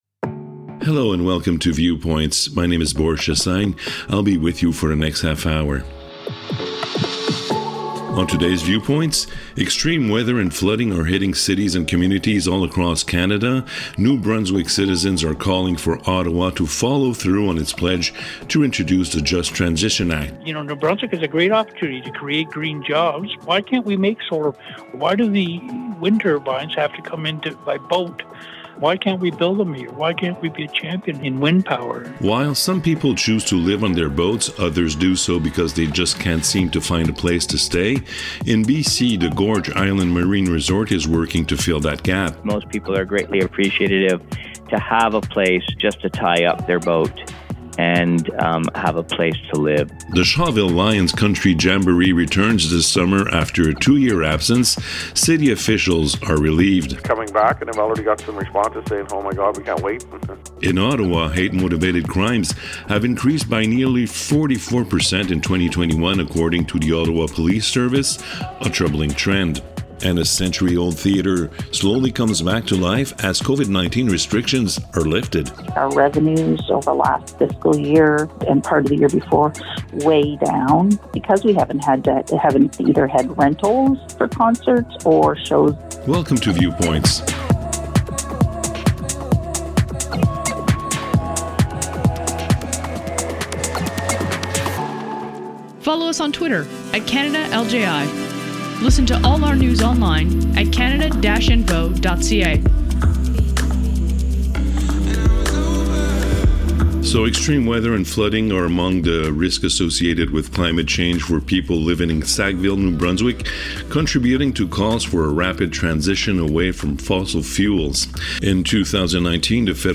The Community Radio Fund of Canada produces a new weekly radio series called Viewpoints, a 30 minute news magazine aired on 30 radio stations across Canada. Viewpoints provides an overview of what’s happening across the country, thanks to some 20 radio correspondents working for the Local Journalism Initiative.